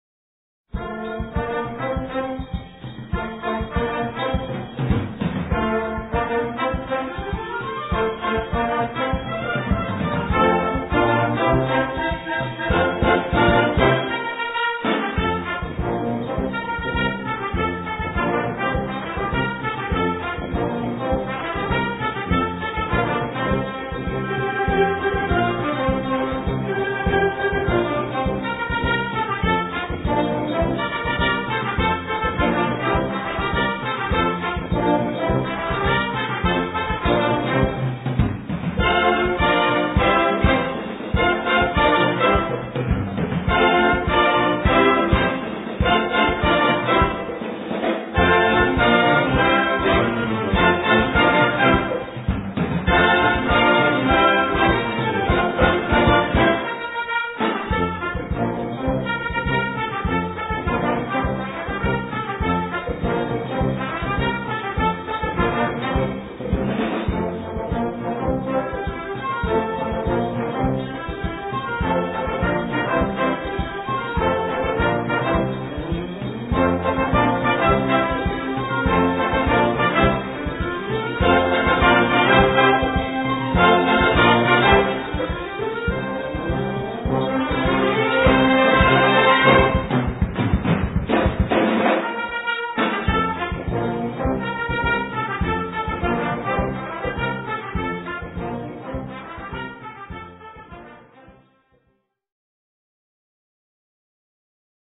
Gattung: Samba
2:50 Minuten Besetzung: Blasorchester Tonprobe